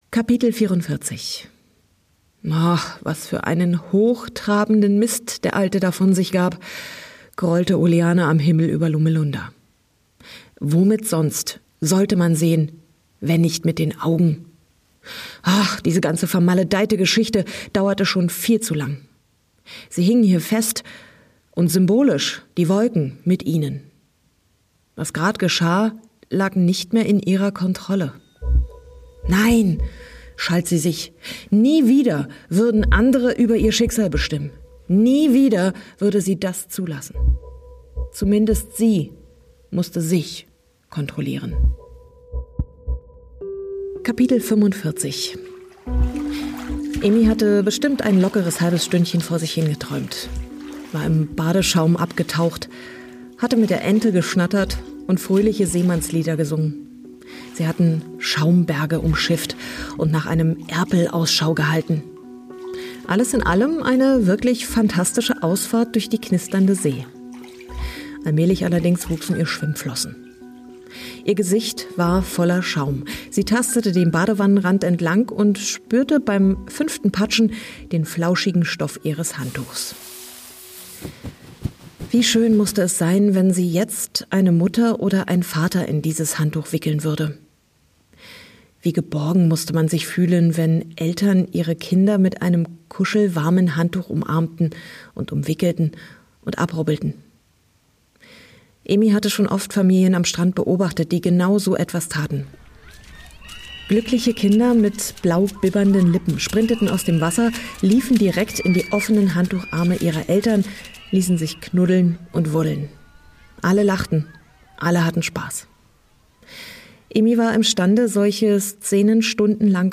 Ein atmosphärisches Hörerlebnis für alle, die sich gern davon und in die Wolken träumen.